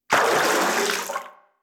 WaterSplash_Out_Short3.wav